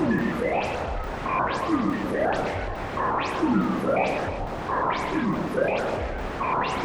STK_MovingNoiseC-140_01.wav